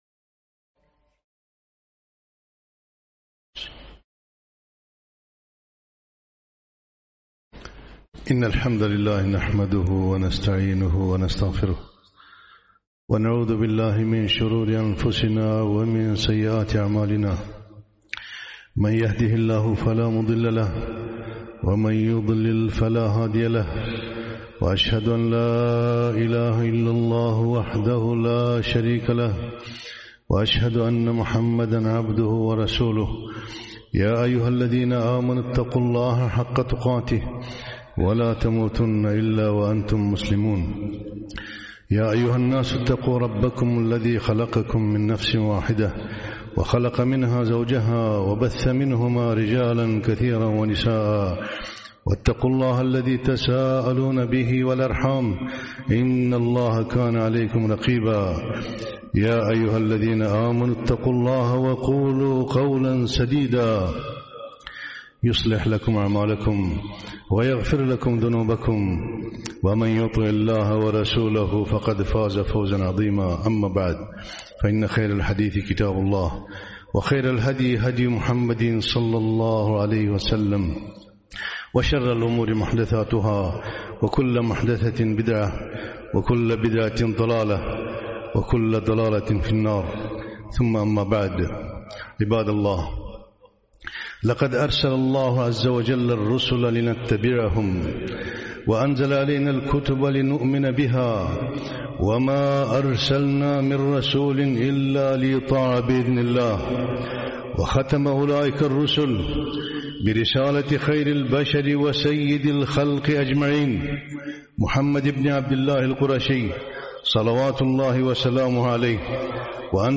خطبة - الاعتصام بالسنة